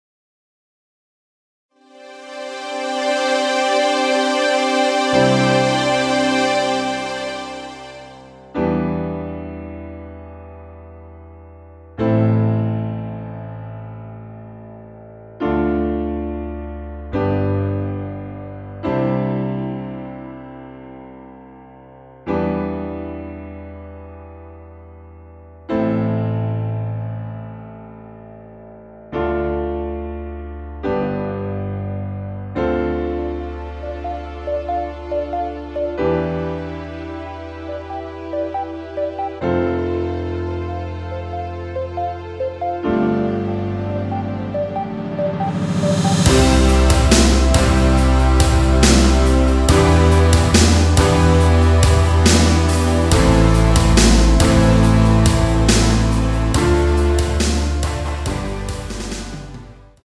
원키에서(+2)올린 MR입니다.
앞부분30초, 뒷부분30초씩 편집해서 올려 드리고 있습니다.